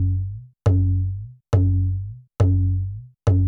TABLA BASS-R.wav